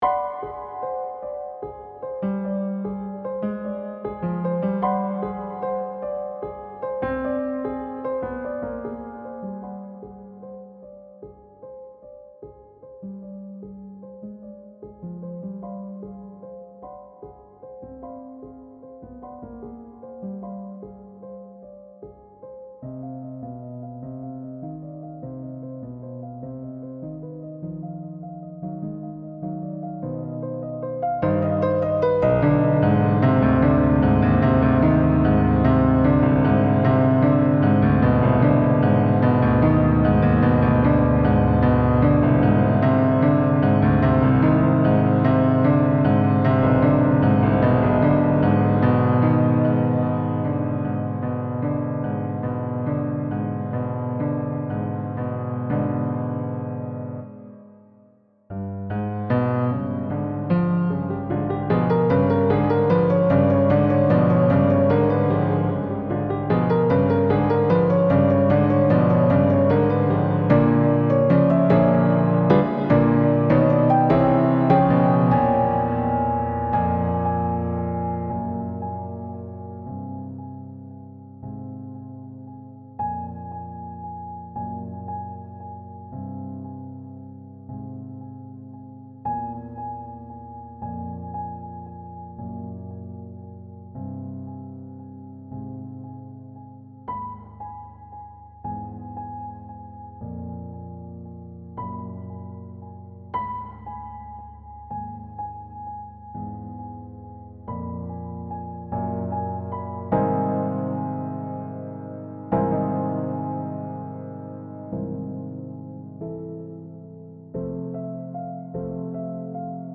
Description: This recording was sequenced in Steinberg Cubasis VST using IK Multimedia's Sampletank for the piano. The sequencing was tweaked to contain a few of the subtle human elements that are necessary to get an appreciation of the piece. Tempo changes are subtle, to reflect ritards written in the music.